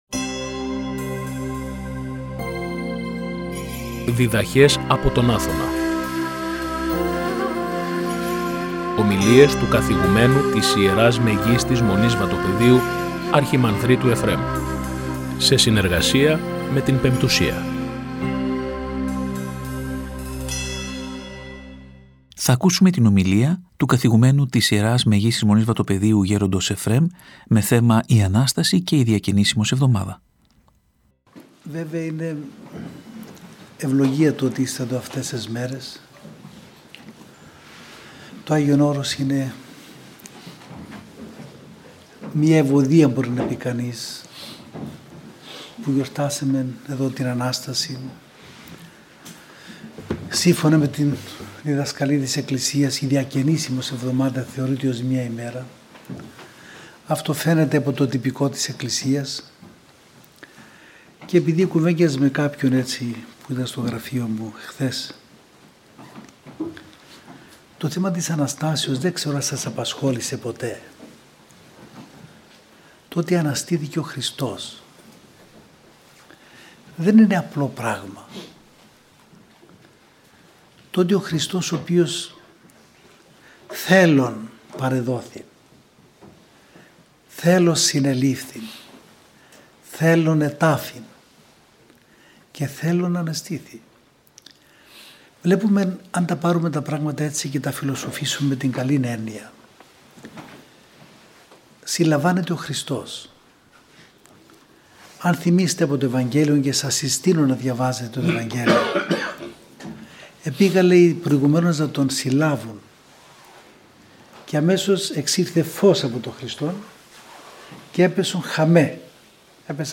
Ομιλία